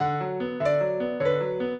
piano
minuet4-8.wav